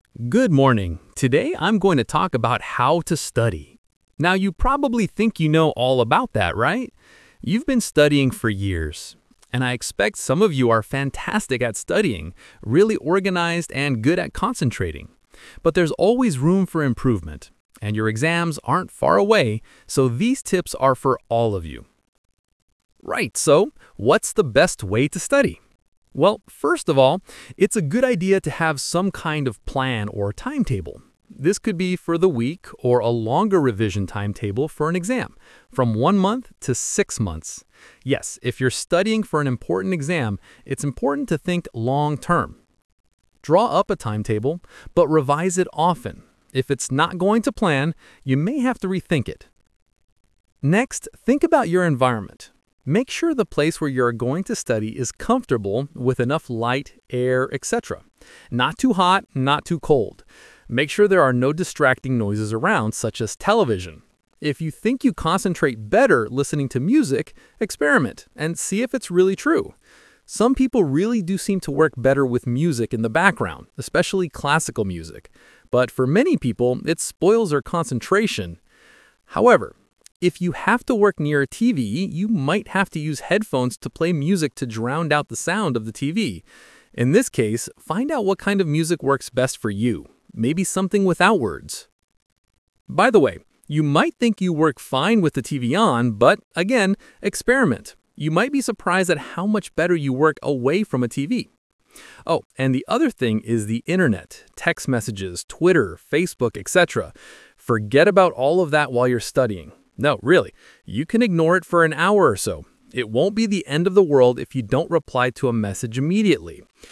Talk/Lecture 2: You will hear a talk about how to study.